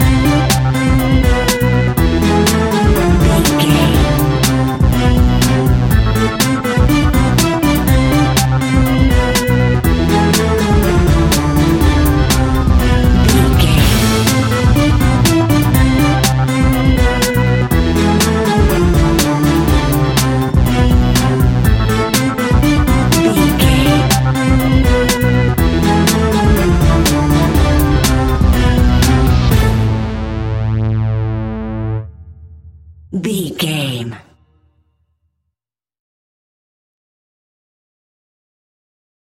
Aeolian/Minor
scary
ominous
haunting
eerie
groovy
funky
electric organ
synthesiser
drums
strings
percussion
spooky
horror music